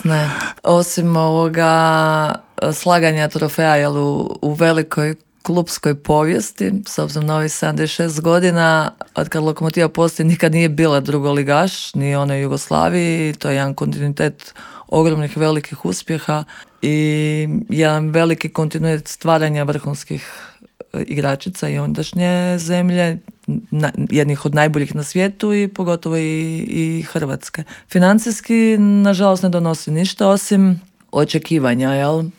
O pripremama za ove dvije dvije važne utakmice sa švicarskim Amicitom iz Zuricha govorila je u Intervjuu Media servisa direktorica kluba i naša legendarna bivša rukometašica Klaudija Bubalo (Klikovac).